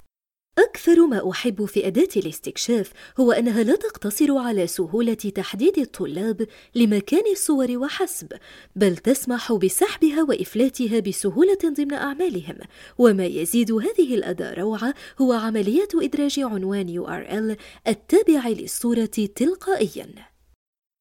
AR RM EL 01 eLearning/Training Female Arabic